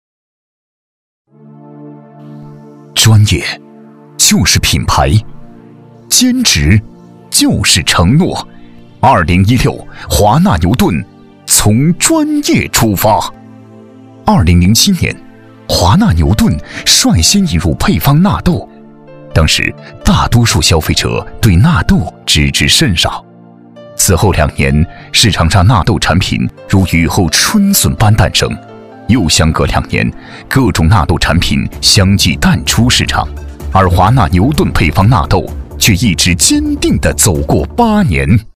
食品公司宣传片配音欣赏
配音试听